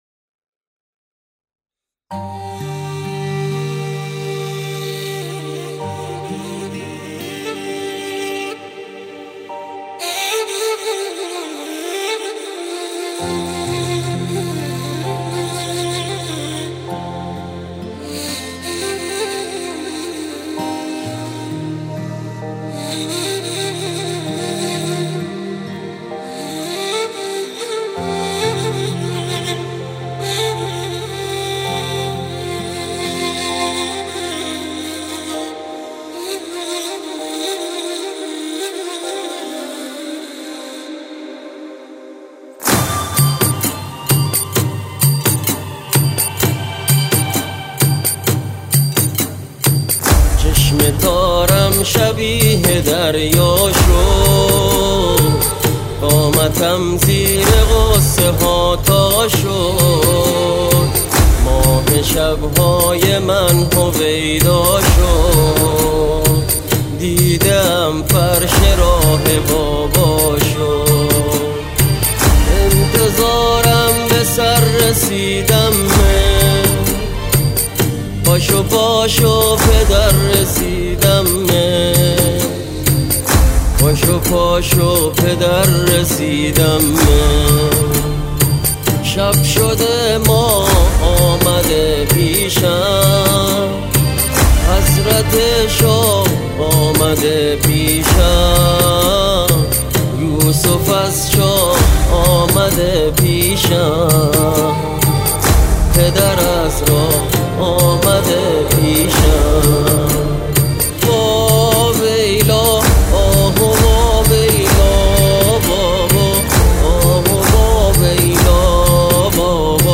▫ نی و ترومپت